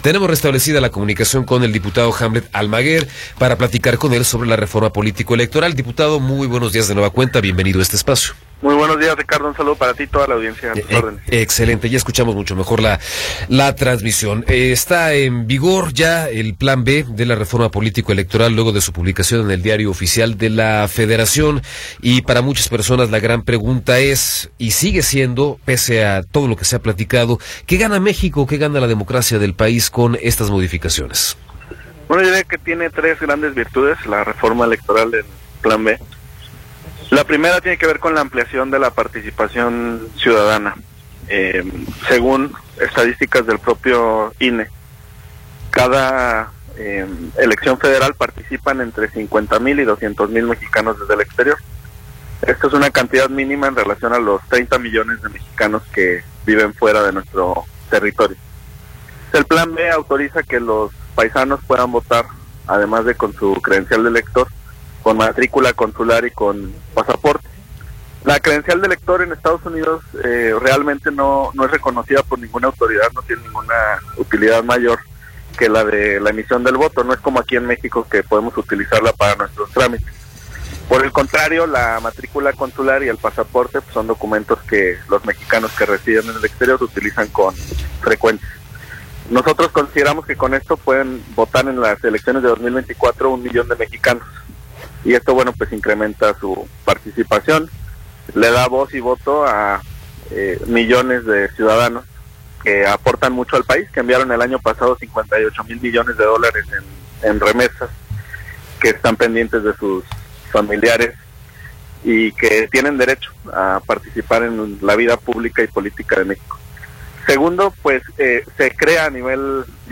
Entrevista con Hamlet García Almaguer
Hamlet García Almaguer, diputado federal por Morena, nos habla sobre la entrada en vigor del llamado “Plan B” de la reforma político-electoral.